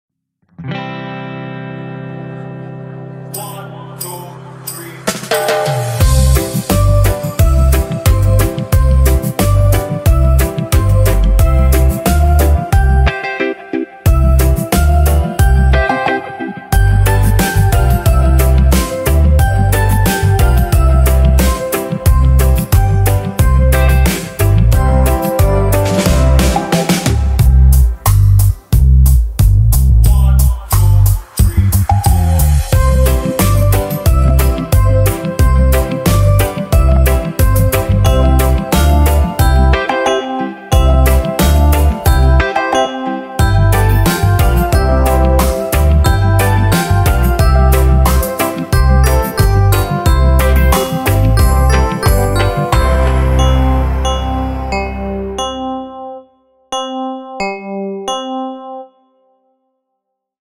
Frere-Jacques-with-instrumental-round.mp3